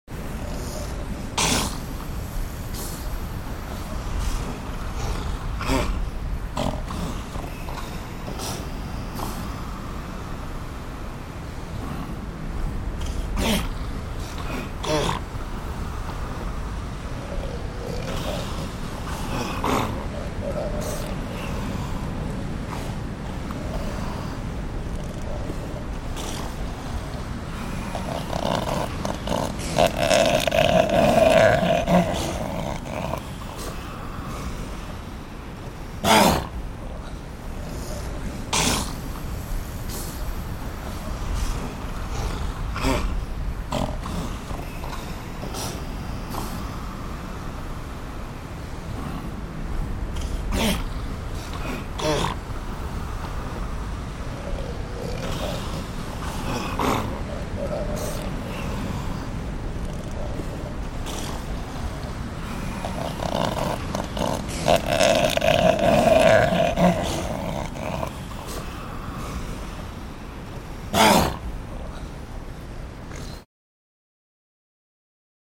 جلوه های صوتی
دانلود صدای حیوان آبی 2 از ساعد نیوز با لینک مستقیم و کیفیت بالا